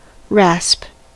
rasp: Wikimedia Commons US English Pronunciations
En-us-rasp.WAV